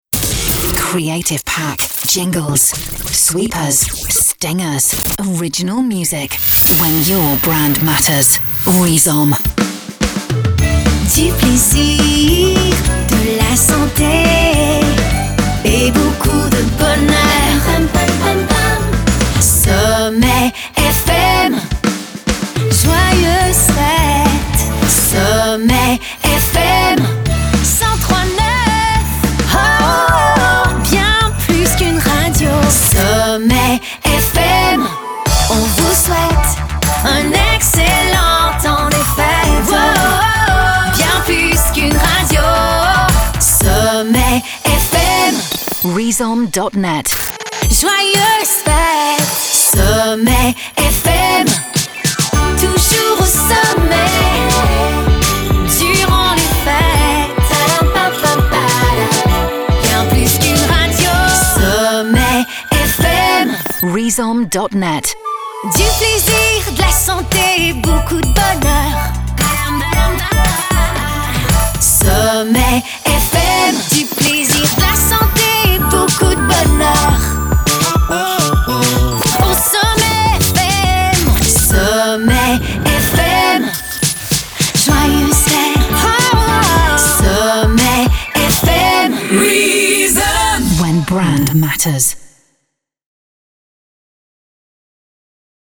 Du "rechanté" / "repiquage" jingles créatifs.